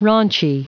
Prononciation du mot raunchy en anglais (fichier audio)